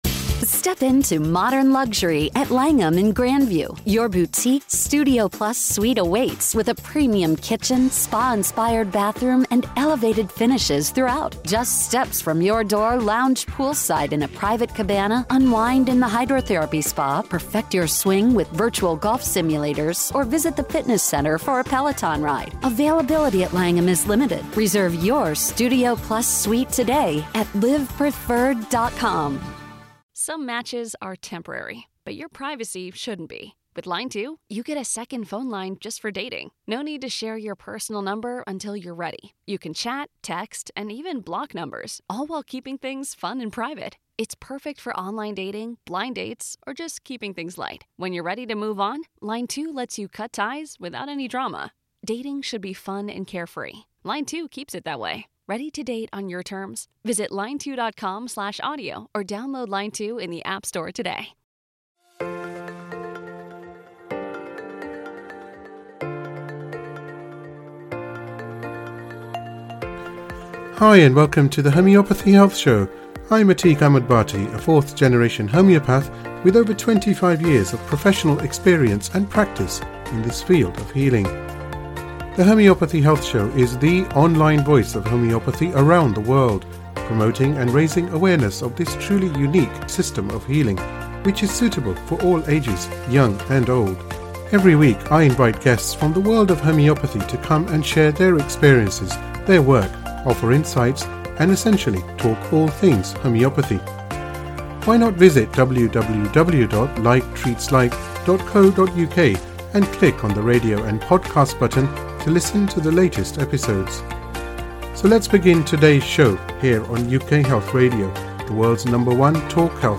for a heartfelt conversation about empowering parents with homeopathy.